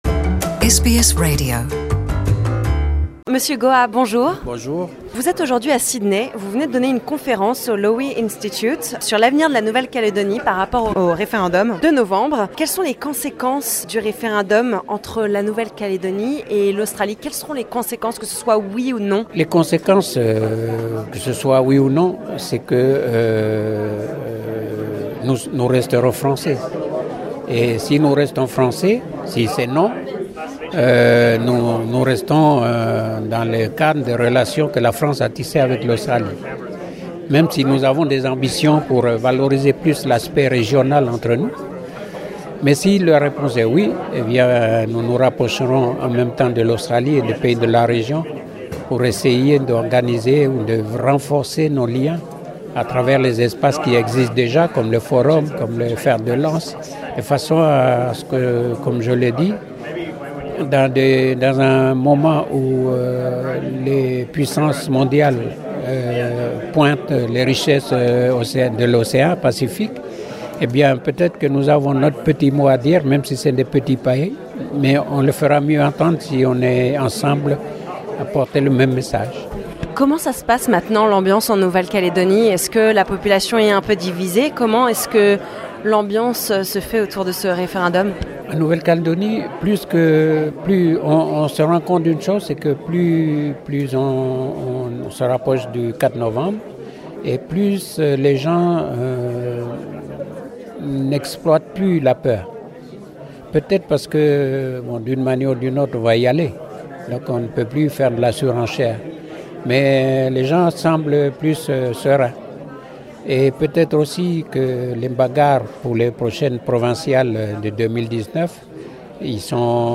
New Caledonia at the crossroads: Daniel Goa, president of the UC spoke to SBS French.